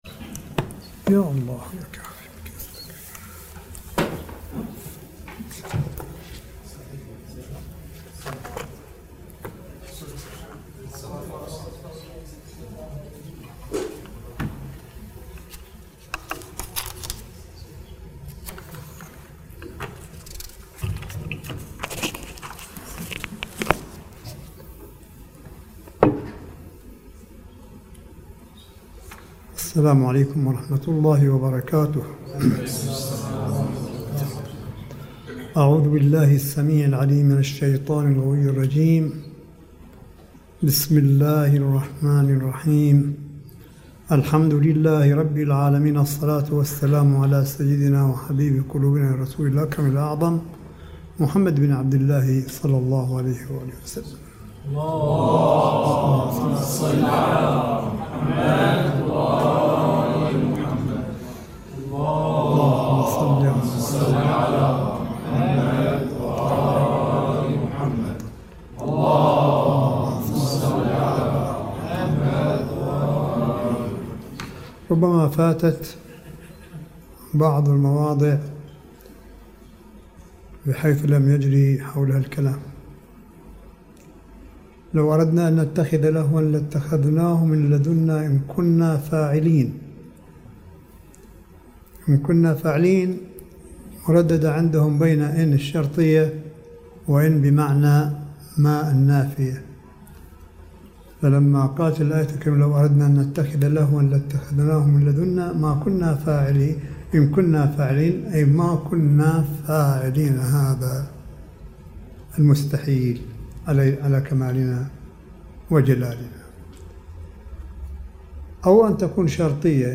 ملف صوتي للحديث القرآني لسماحة آية الله الشيخ عيسى أحمد قاسم حفظه الله بقم المقدسة – 13 شهر رمضان 1440 هـ / 19 مايو 2019م